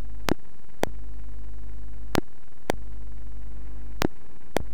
Metronome Circuit
The output sound is distorted to emphasize the pulses.
This produces a ringing effect on the output pulse and gives the circuit a characteristic "tick" sound. A sound sample recorded under Audacity on PCLinuxOS is shown below.
The 1k resistor R8 then acts as a low impedance shunt at the input of the integrator and produces a characteristic "tock" effect.
metronome.wav